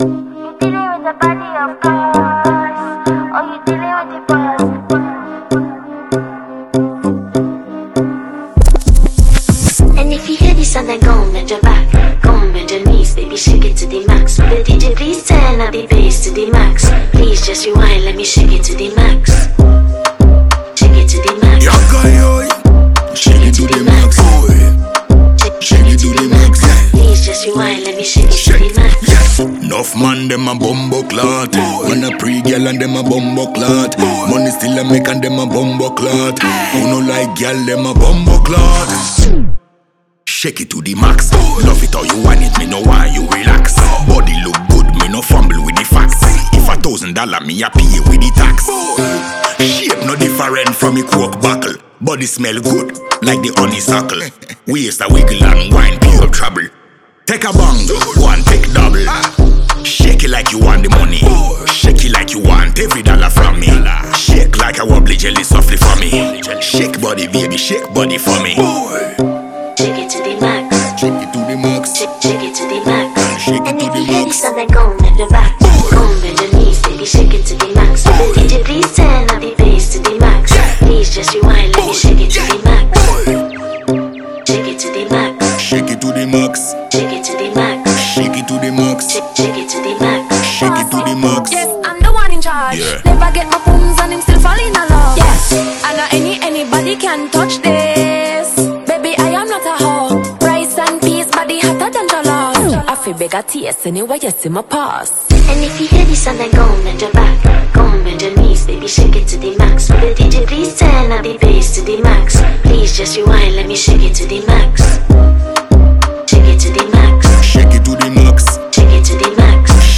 freestyle version